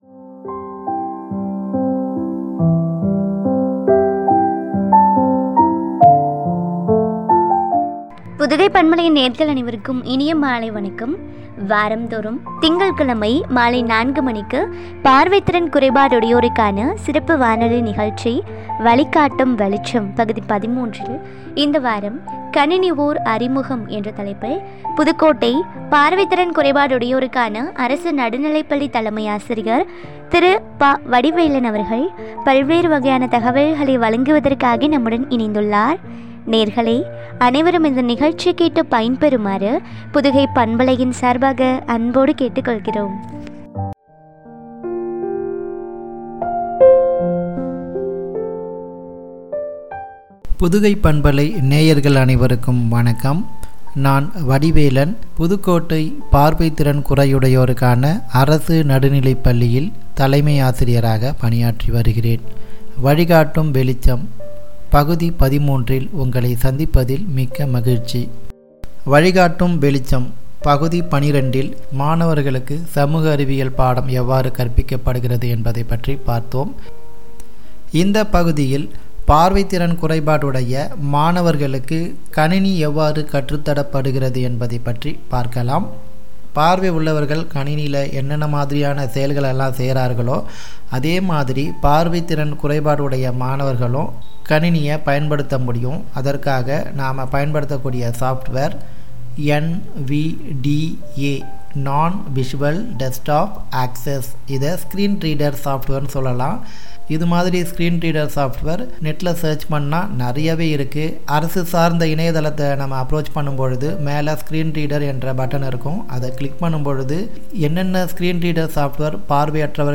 பார்வை திறன் குறையுடையோருக்கான சிறப்பு வானொலி நிகழ்ச்சி
” “கணினி ஓர் அறிமுகம்” குறித்து வழங்கிய உரையாடல்.